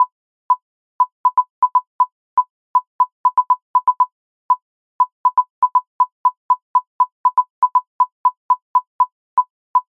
Many hours later, and now I have a Euclidean Rhythm Generator sequencing blips.